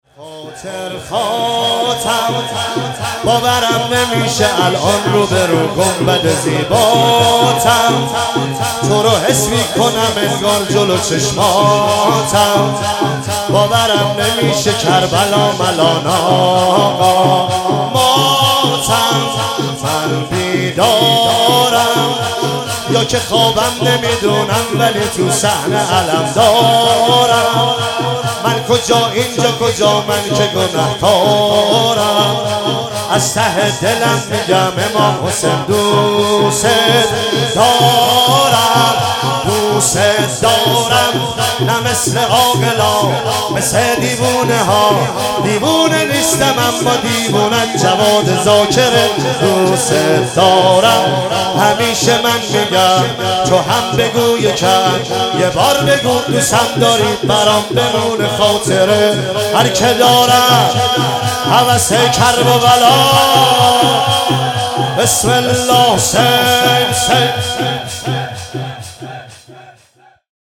مراسم شب ۲ صفر ۱۳۹۷
دانلود شور